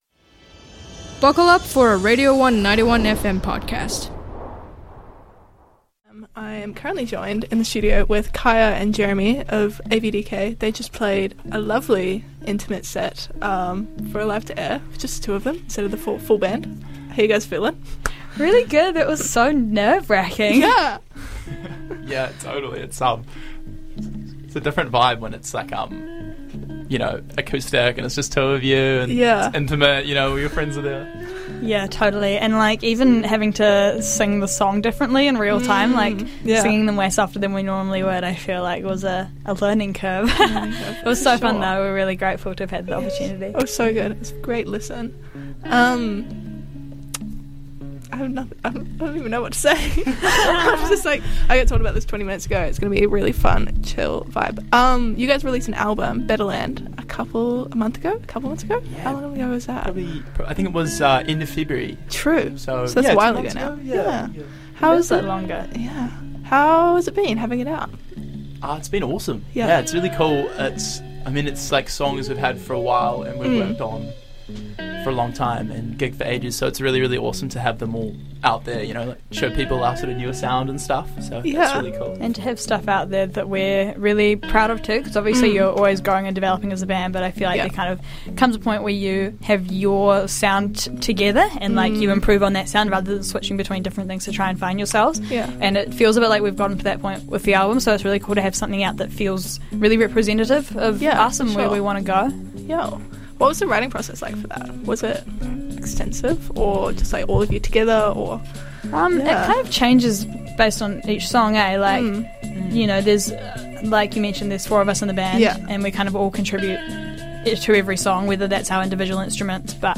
INTERVIEW: The Audio Visual Drop Kicks after Radio One Live to Air for NZMM 2025